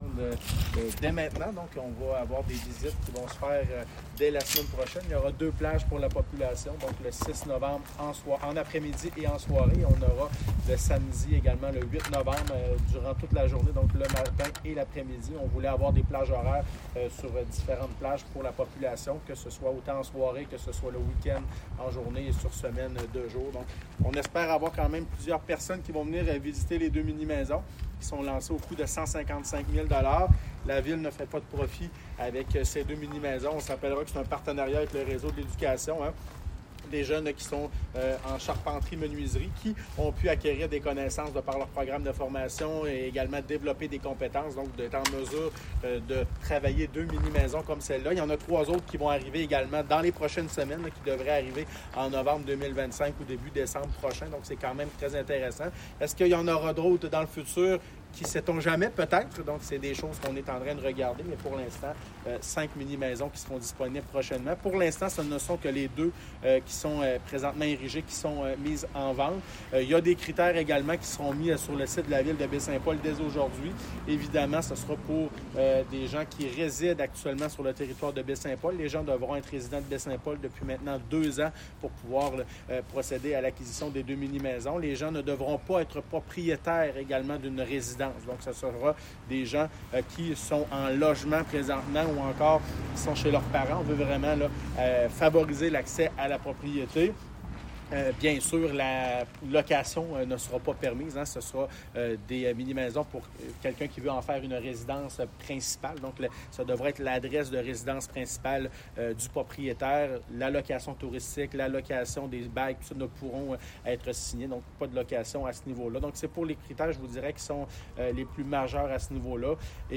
Le maire de Baie-Saint-Paul, Michaël Pilote, a rencontré les médias ce matin et explique très bien le processus de vente des minimaisons sur la rue Alfred-Morin.